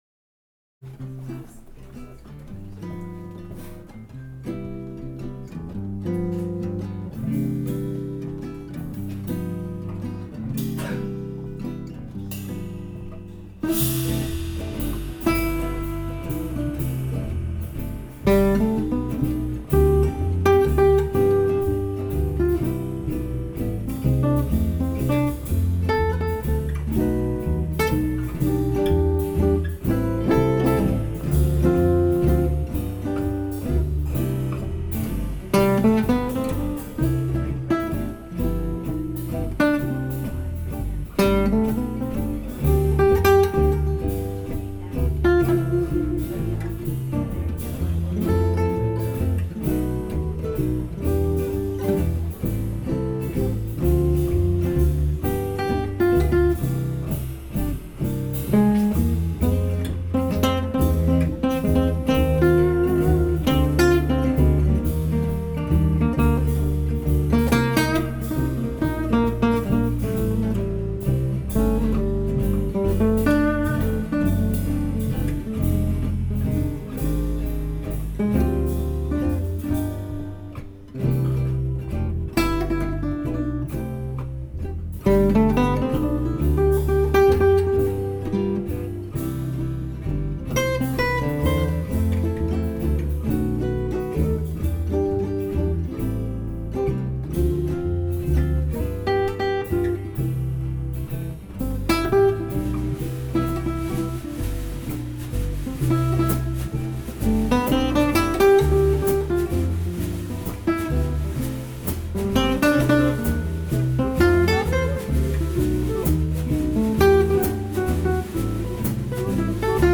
Guitar
Acoustic Double Bass.